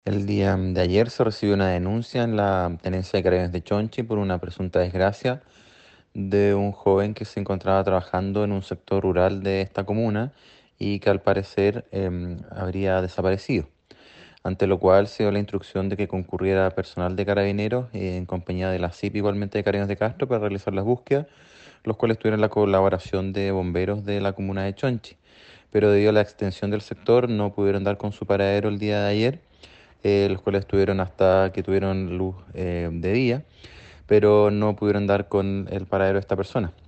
Así lo expresó el fiscal de Castro Luis Barría, quien certificó que el día 24 de enero ya se había activado una búsqueda de emergencia a la que acudieron efectivos de Carabineros de Chonchi y Castro, sin embargo no fue posible encontrarlo dada la oscuridad a eso de las 21 horas en adelante.